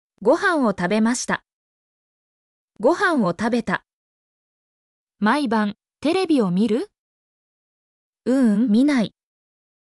mp3-output-ttsfreedotcom-60_4TPonvgl.mp3